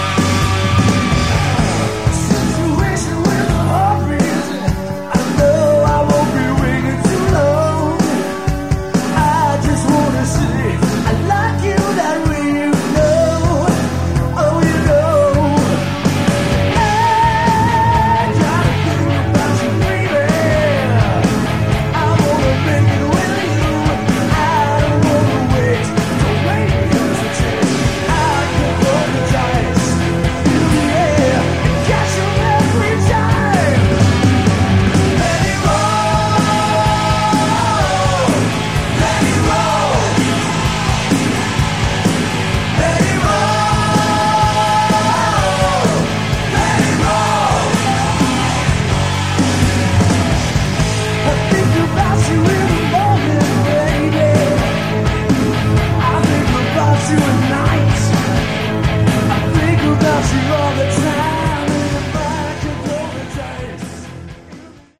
Category: Hard Rock
lead vocals, guitars
bass, backing vocals
drums, percussion (3 bonus tracks)
keyboards, backing vocals (3 bonus tracks)
rhythm and lead guitars (3 bonus tracks)